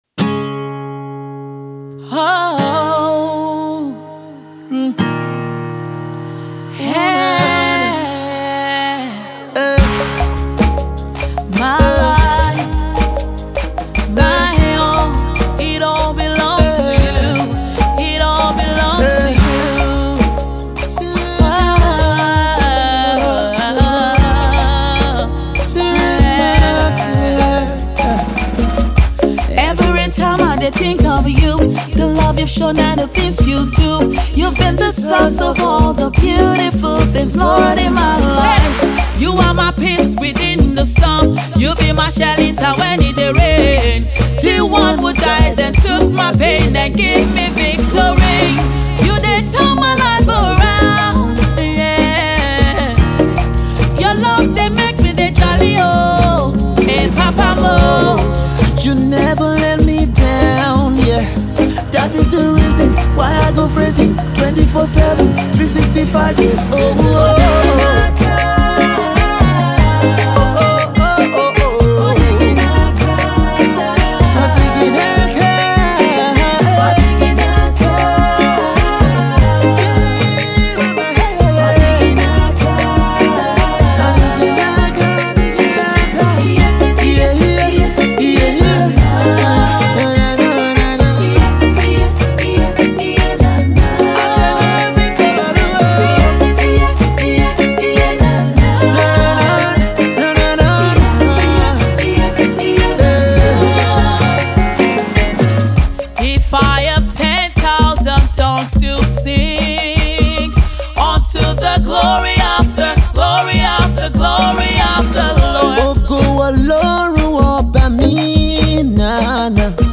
It is a song that uplifts no matter the situation.